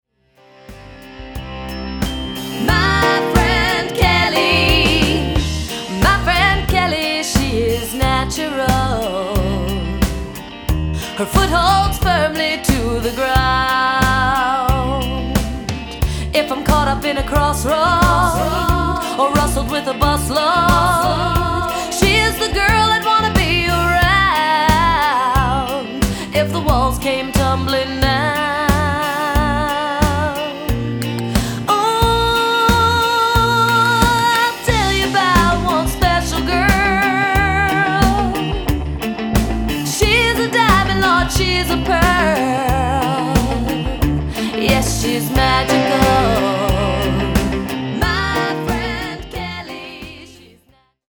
Vocals
Guitar, bass, keyboards, and drums